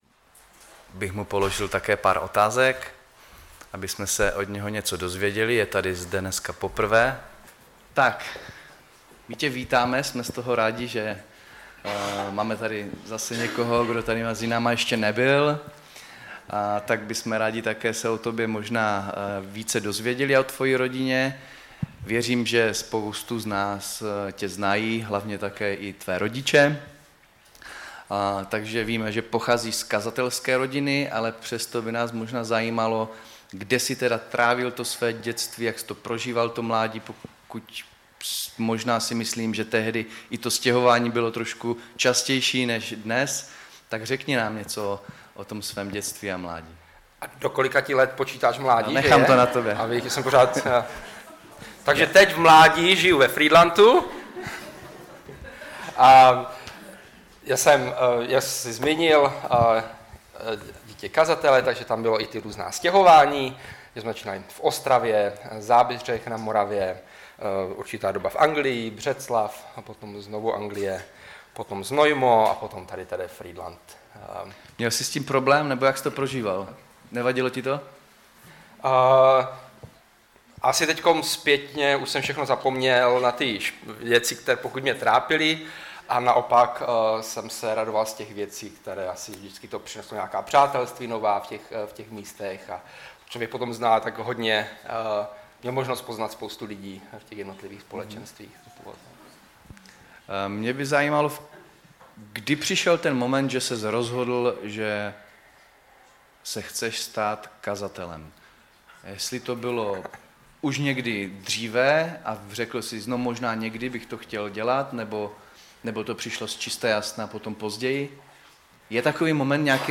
Kázání
ve sboře Ostrava-Radvanice